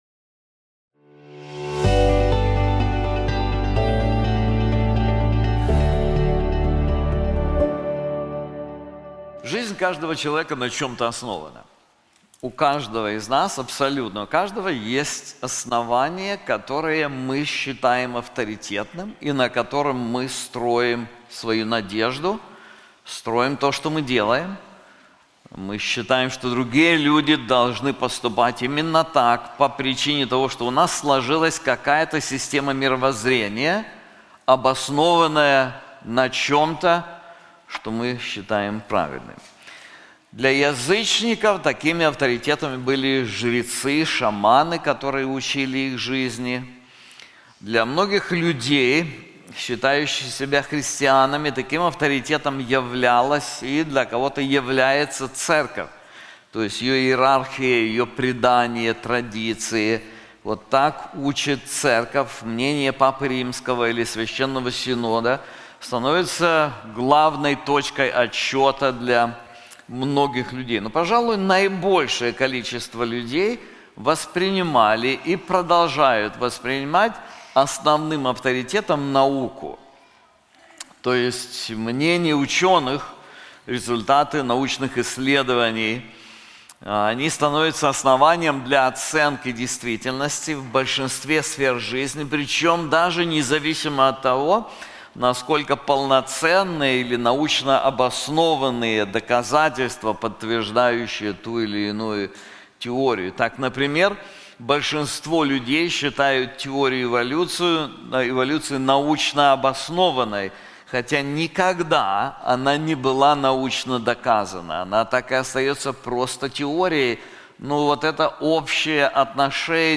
This sermon is also available in English:Scripture and the World Around Us • Genesis 1:1-31